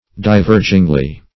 divergingly - definition of divergingly - synonyms, pronunciation, spelling from Free Dictionary Search Result for " divergingly" : The Collaborative International Dictionary of English v.0.48: Divergingly \Di*ver"ging*ly\, adv.